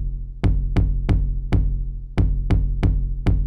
Index of /90_sSampleCDs/Best Service ProSamples vol.54 - Techno 138 BPM [AKAI] 1CD/Partition C/UK PROGRESSI
TRASH TOM -R.wav